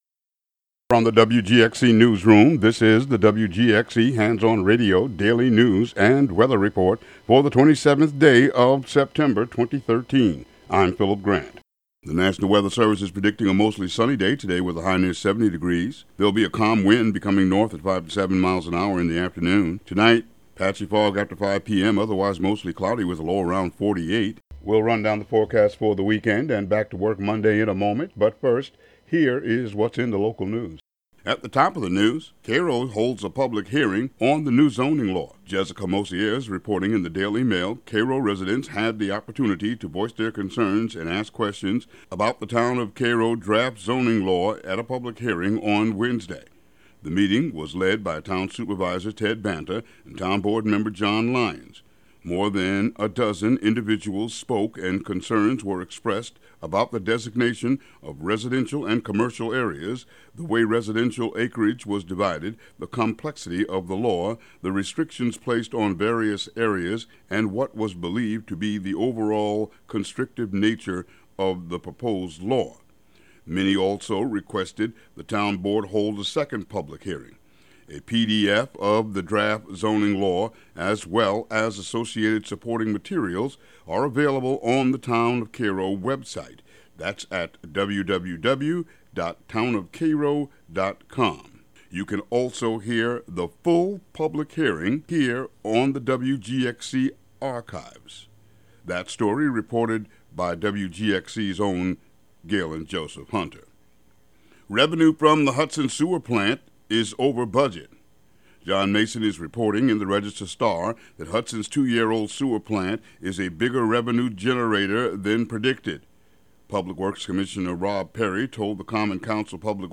Local news and weather for Friday, September 27, 2013.